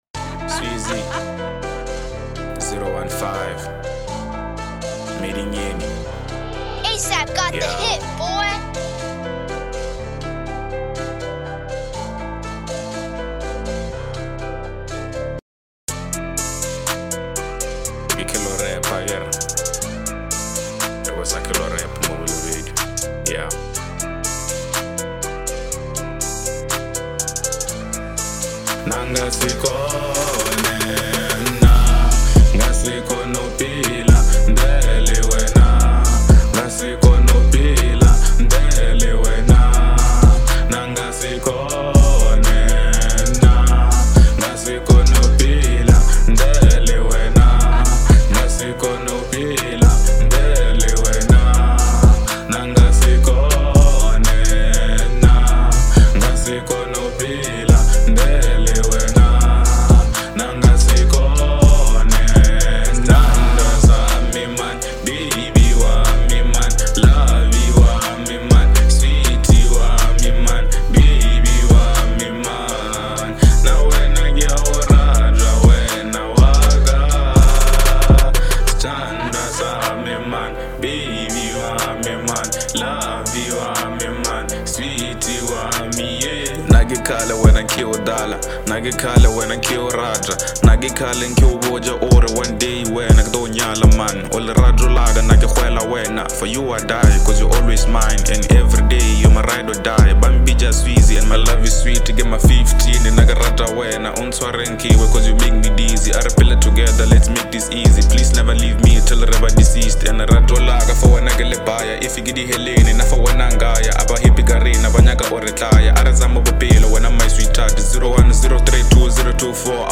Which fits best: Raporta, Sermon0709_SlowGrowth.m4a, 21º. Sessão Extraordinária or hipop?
hipop